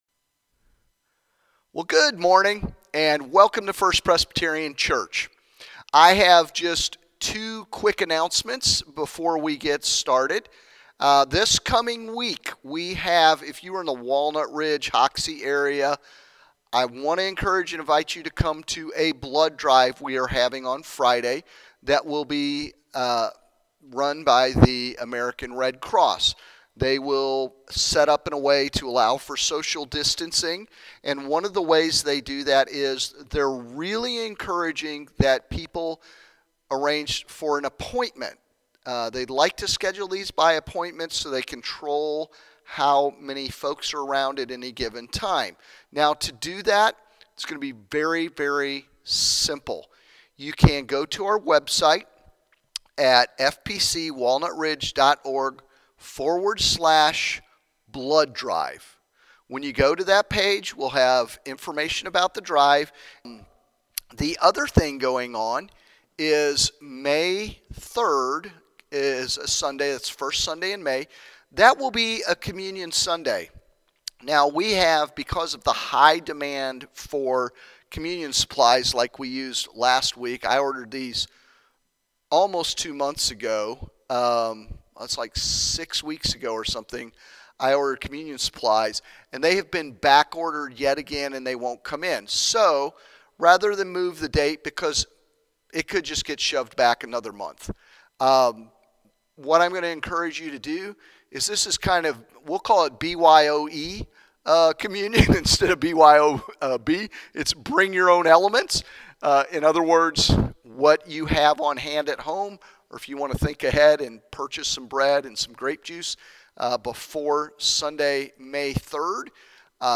We’ll look at how God uses things we know and love, such as, people we know and love, scripture, and routine experiences of his presence. If you have enjoyed this sermon and received a blessing by listening, please share this message with someone else.